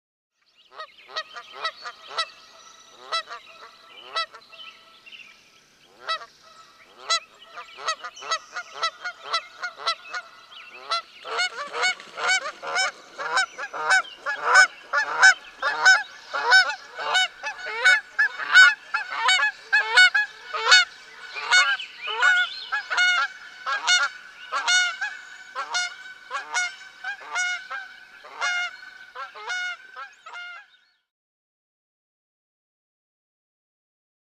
دانلود آهنگ غاز 1 از افکت صوتی انسان و موجودات زنده
دانلود صدای غاز 1 از ساعد نیوز با لینک مستقیم و کیفیت بالا
جلوه های صوتی